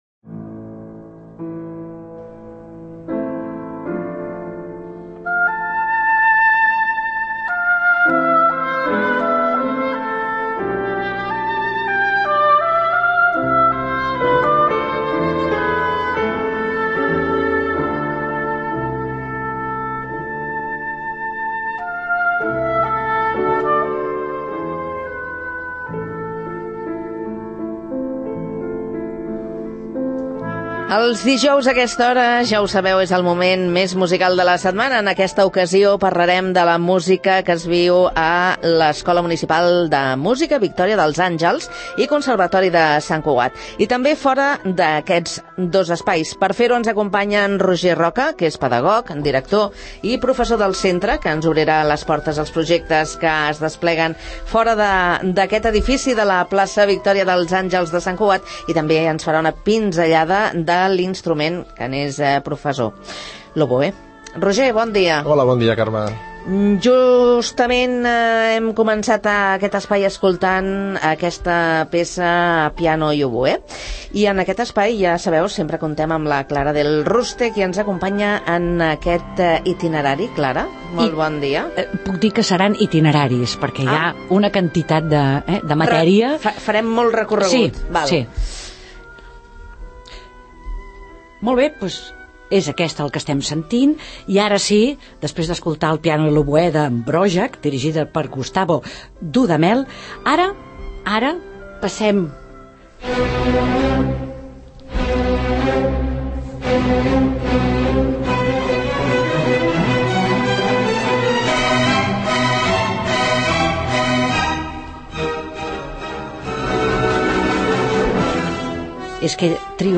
L’Escola Municipal de Música Victòria dels Àngels i Conservatori de Sant Cugat ha presentat els seus projectes musicals al magazín ‘Faves comptades’.